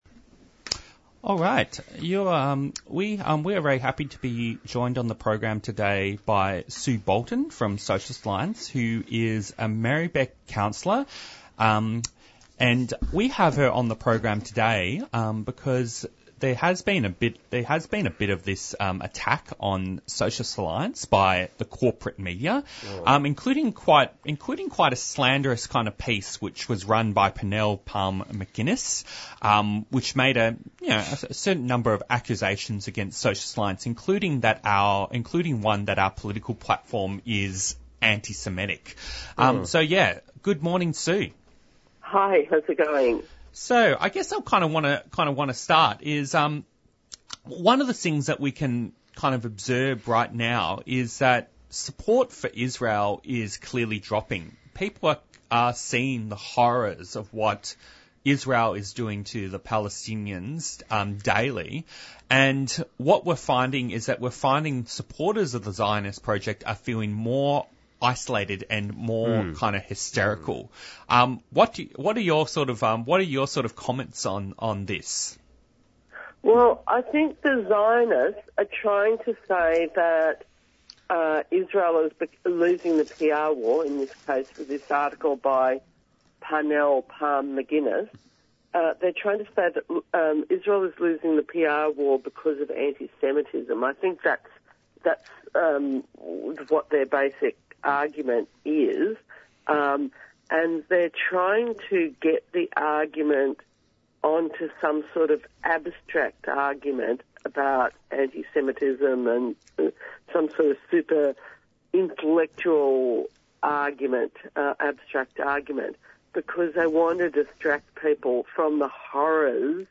Discussion from the presenters responding to the following developments.
Interviews and Discussions Sue Bolton, Merri-Bek councillor and member of Socialist Alliance responds to the right-wing slander against the party which alledged that their opposition to Israel is based on anti-semitism.